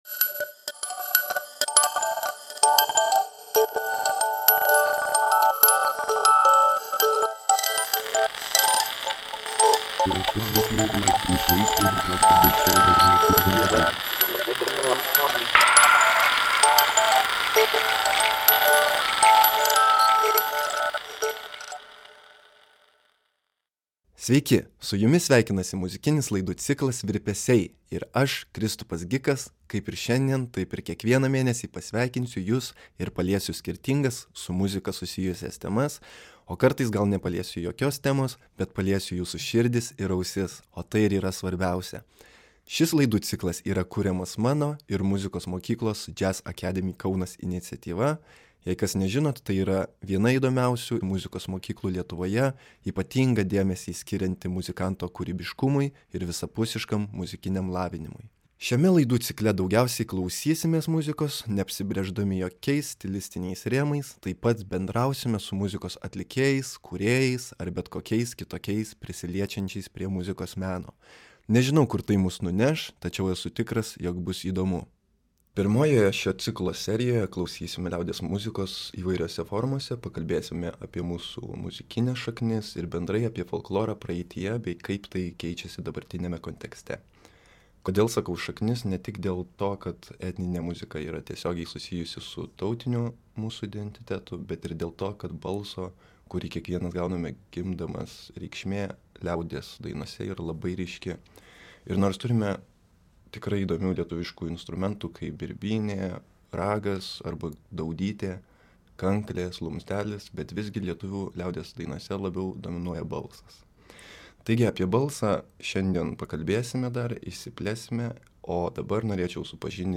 Pokalbis
Podcast’e panaudoti tik žemiau nurodytų autorių muzikos fragmentai, gavus jų sutikimą.